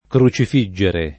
crocifiggere [kro©if&JJere] v.; crocifiggo [kro©if&ggo], ‑gi — pass. rem. crocifissi [kro©if&SSi]; part. pass. crocifisso [kro©if&SSo] — ant. crucifiggere [